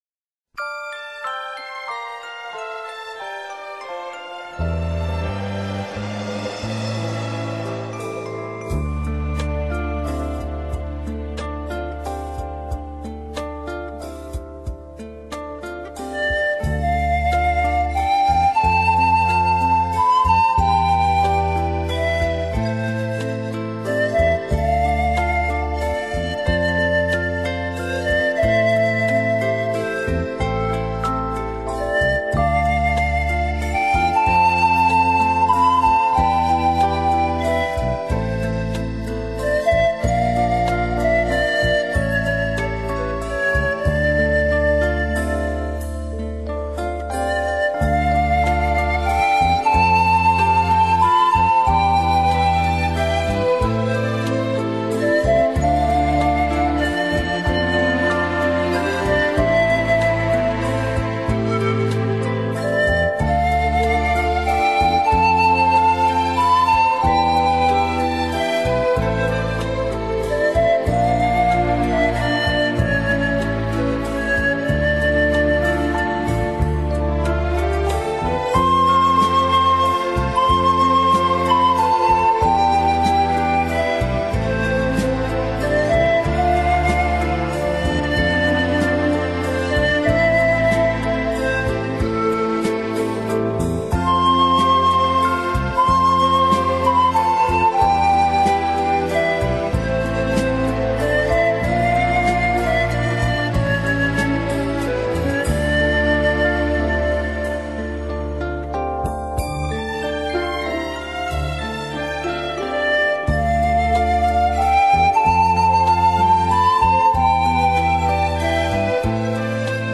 这样的音 质，适合表现空灵的意境，纷飞的思绪，缥缈的梦境，醇厚的深情。